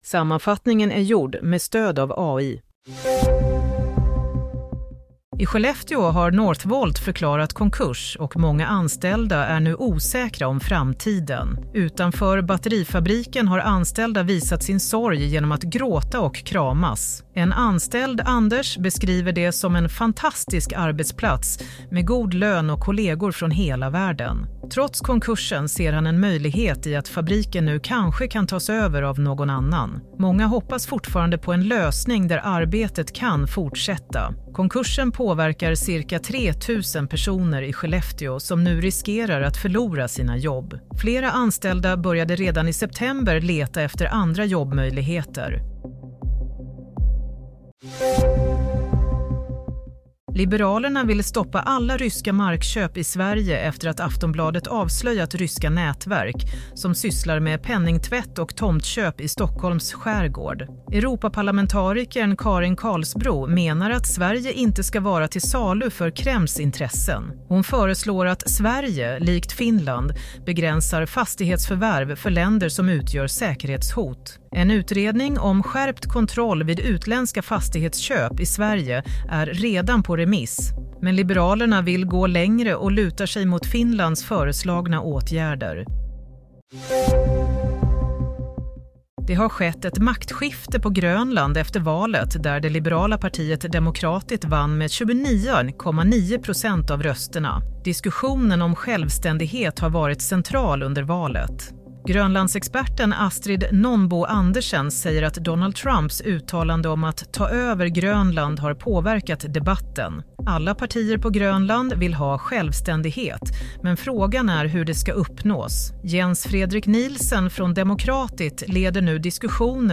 Nyhetssammanfattning 13 mars 08.00